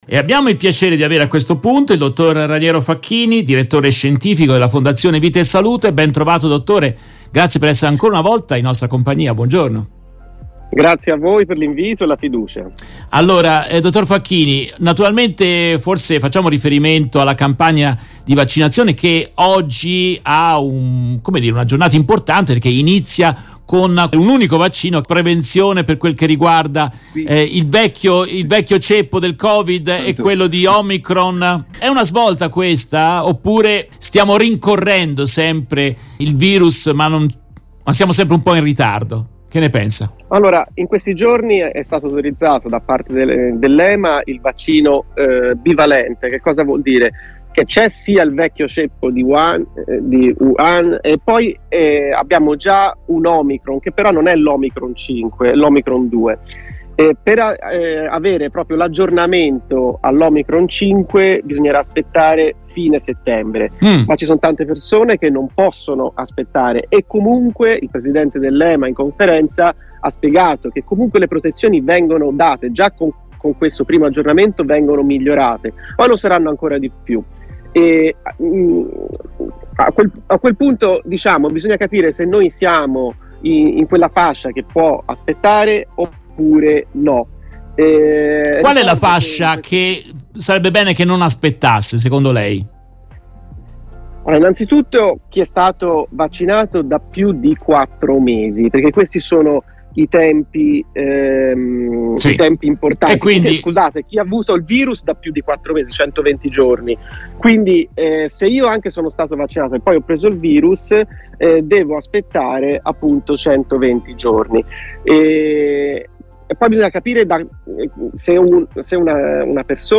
In questa intervista tratta dalla diretta RVS del 12 settembre 2022